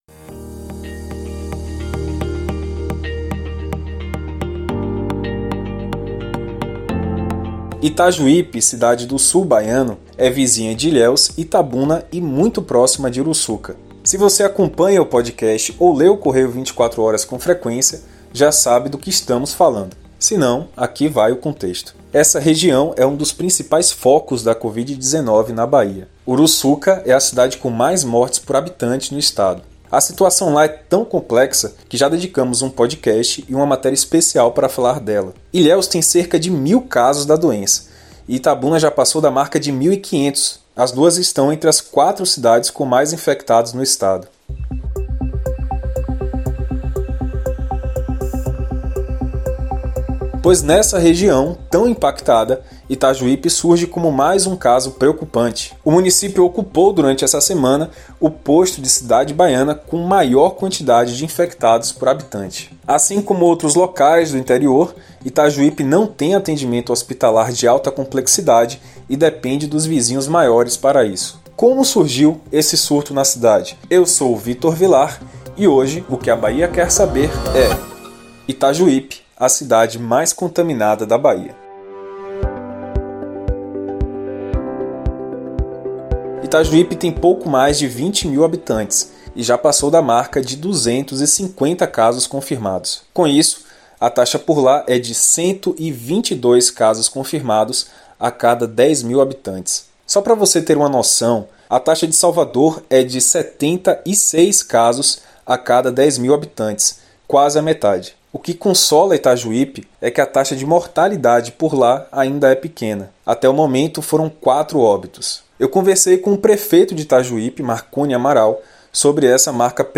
A Agência Correio entrevistou o prefeito de Itajuípe, Marcone Amaral, para falar do problema enfrentado naquela cidade.